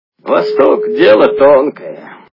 » Звуки » Из фильмов и телепередач » Белое солнце пустыни - Восток дело тонкое
При прослушивании Белое солнце пустыни - Восток дело тонкое качество понижено и присутствуют гудки.